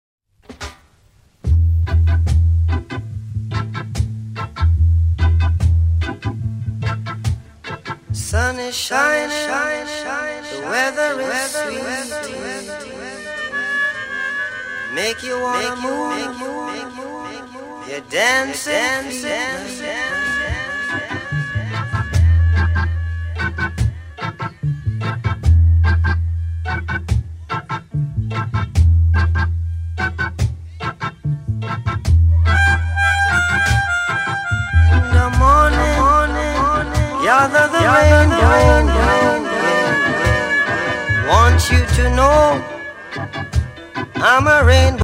# DUB / UK DUB / NEW ROOTS